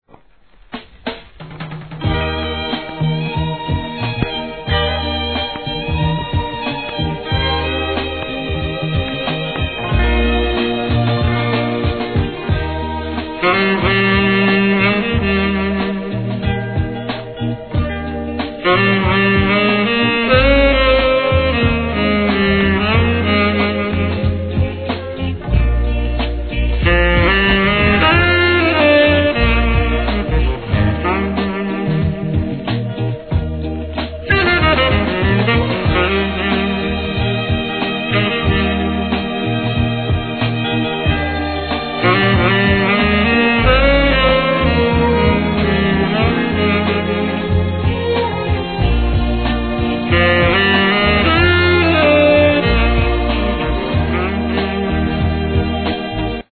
REGGAE
素晴らしいSAX inst.物!!